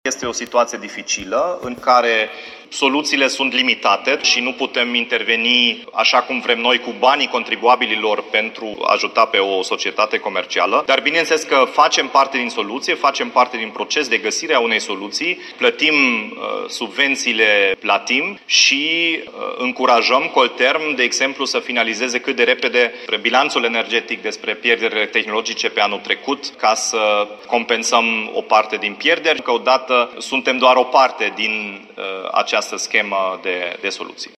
Primarul Dominic Fritz dă asigurări că Primăria Timișoara își va achita partea de subvenție către Colterm.
Dominic-Fritz-Colterm.mp3